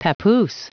Prononciation du mot papoose en anglais (fichier audio)
Prononciation du mot : papoose